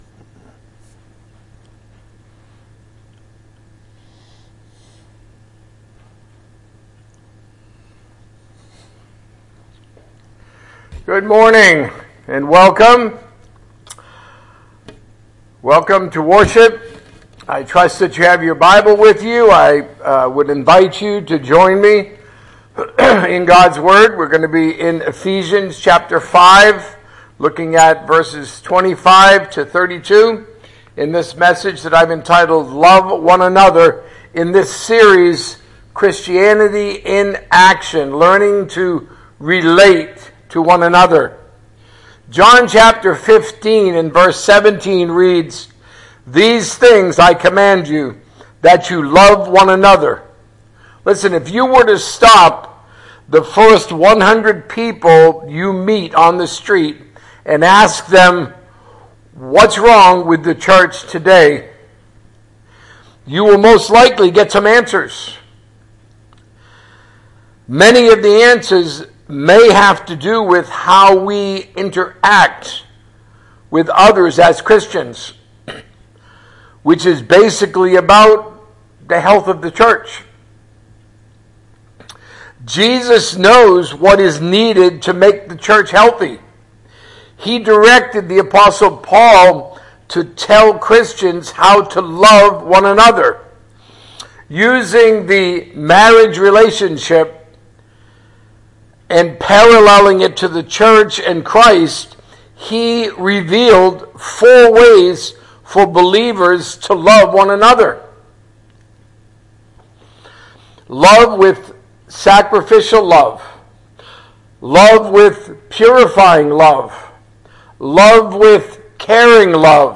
A message from the series "Christianity in Action."